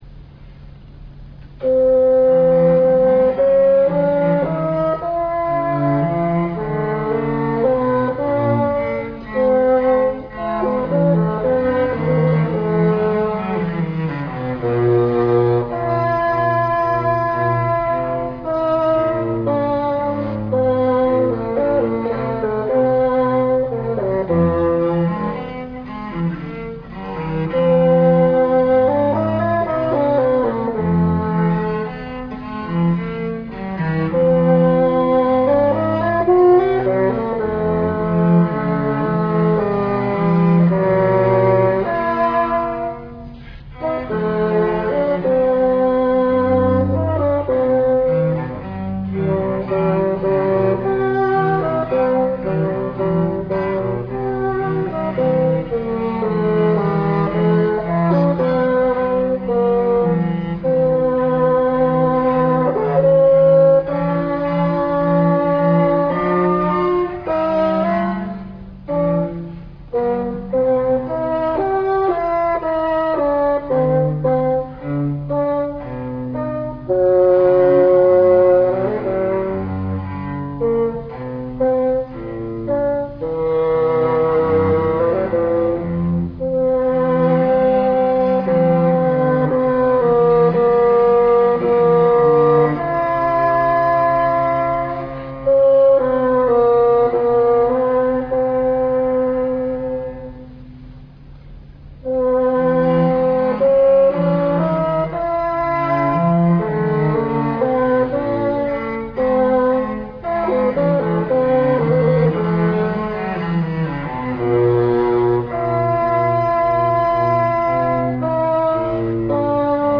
１９９９年８月８日・八幡市文化センター小ホールにて行われた八幡市民オーケストラ室内楽の集い〜真夏の真昼のコンサート〜での演奏。
（PCM 11,025 kHz, 8 ビット モノラル/RealPlayer3.0以上でお聞き下さい）